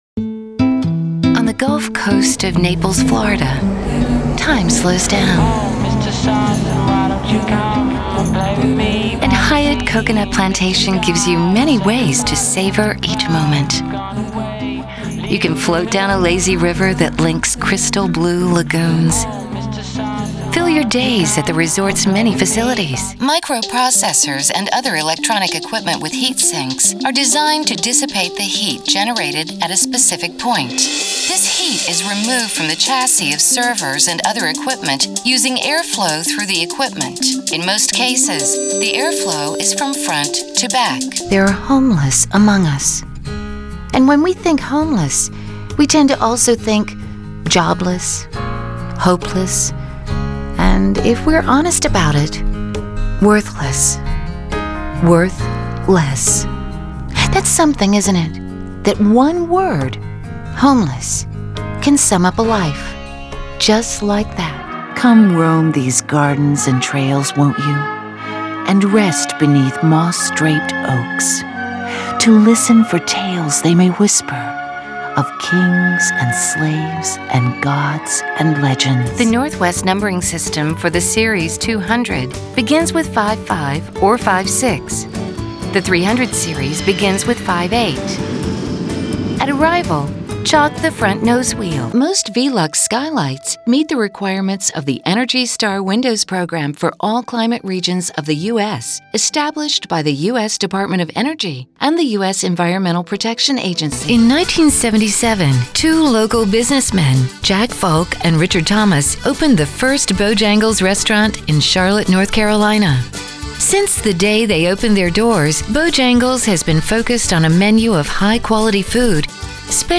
click for commercial demo click for narration demo